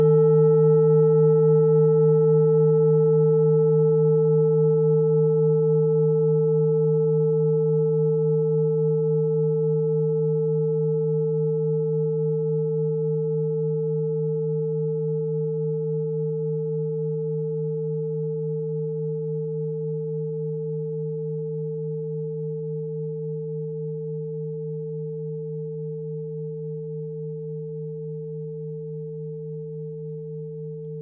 Klangschale TIBET Nr.11
Sie ist neu und ist gezielt nach altem 7-Metalle-Rezept in Handarbeit gezogen und gehämmert worden..
(Ermittelt mit dem Filzklöppel oder Gummikernschlegel)
Wasserstoffgamma Frequenz
klangschale-tibet-11.wav